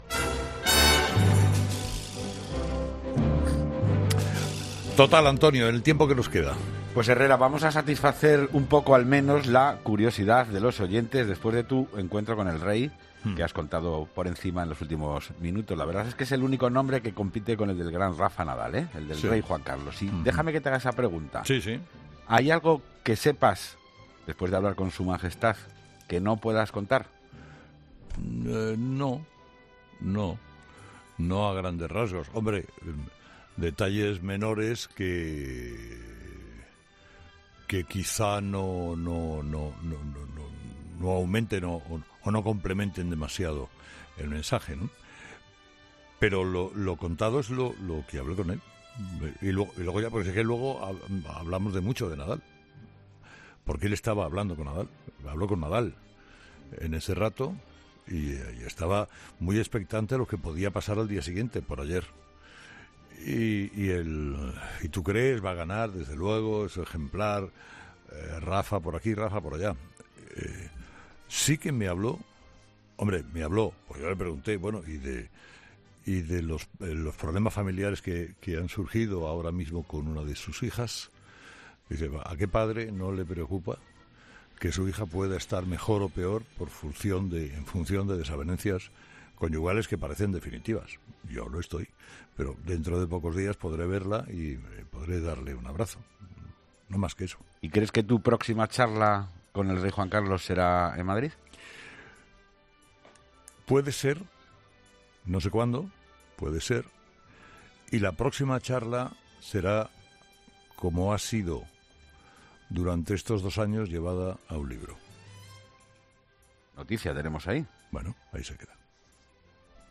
La tertulia de los oyentes